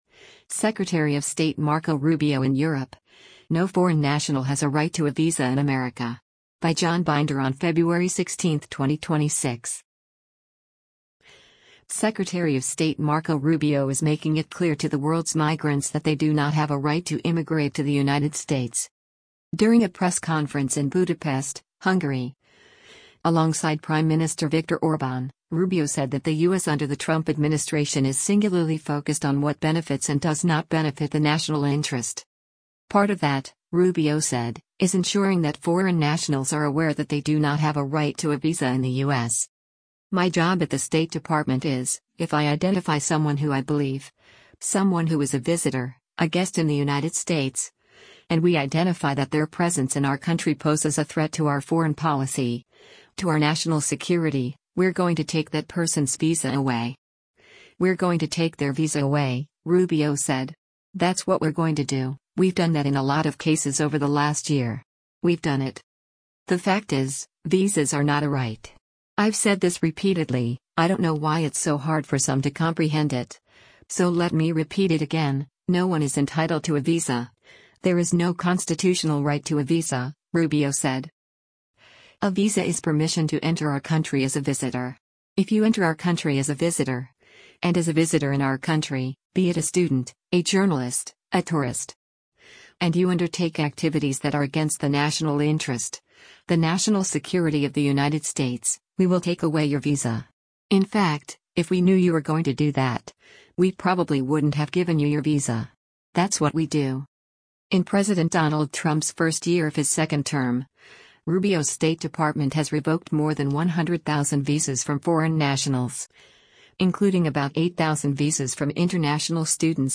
During a press conference in Budapest, Hungary, alongside Prime Minister Viktor Orbán, Rubio said that the U.S. under the Trump administration is singularly focused on what benefits and does not benefit the national interest.